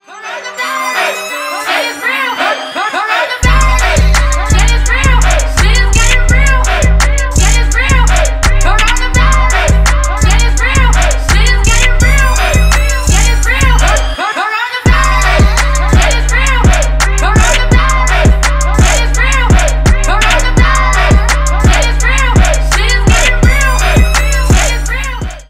Поп Музыка
клубные # громкие
весёлые